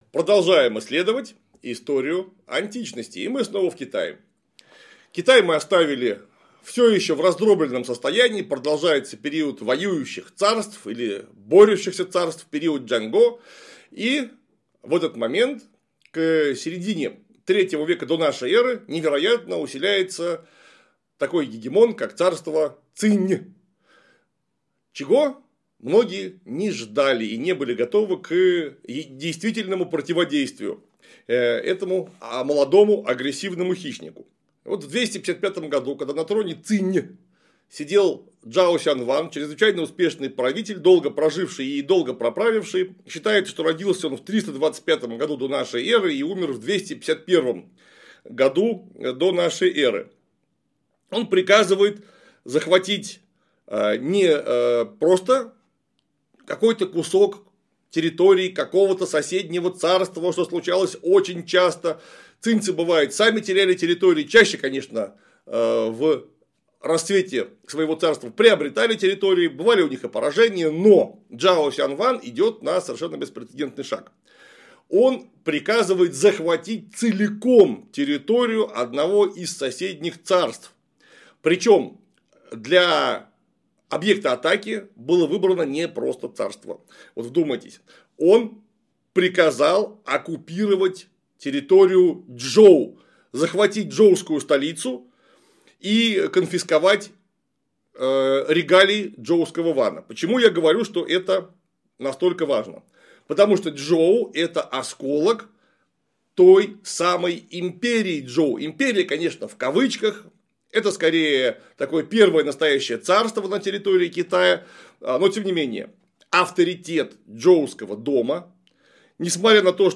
Урок 141. Предпосылки периода Воюющих Царств.